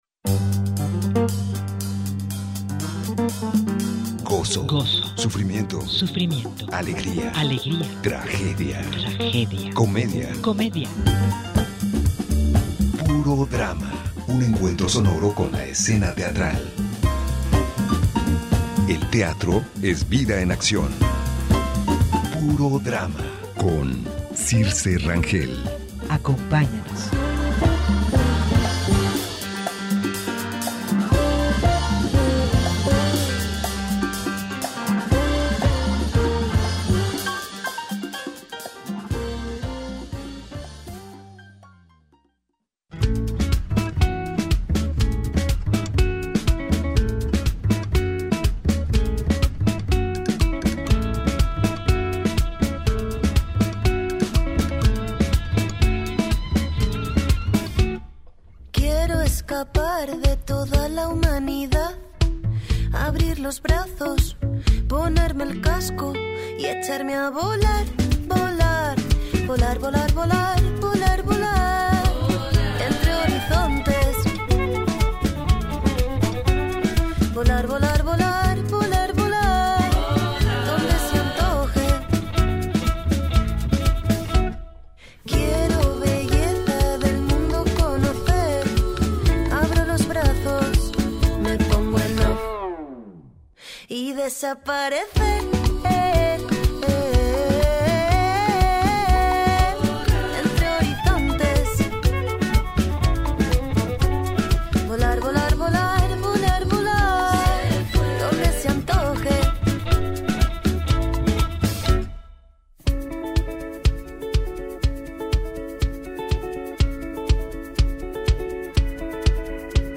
Inicia este mes de febrero y son ellos quienes nos cuentan los detalles en esta conversación.